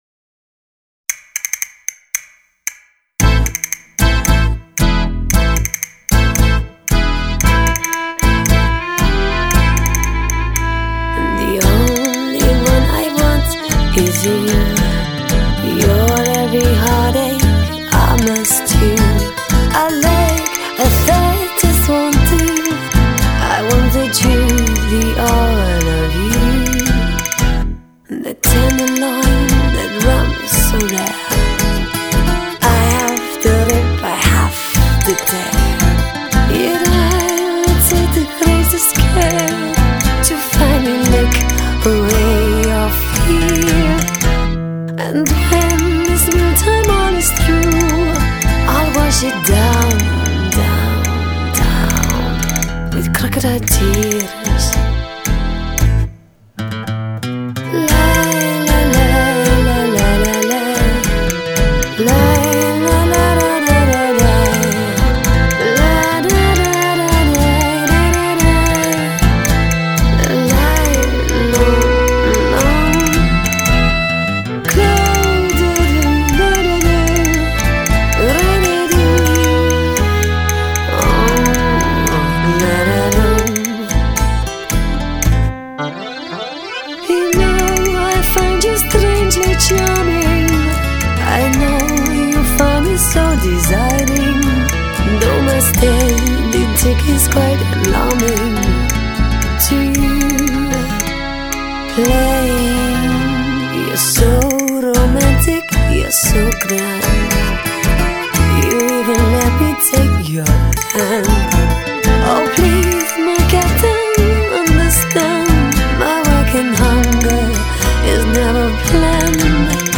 全新冰釀濃縮巴莎諾瓦、美聲天籟、法國香頌、拉丁流行、義式情歌等16首聽眾票選最佳異國珍藏曲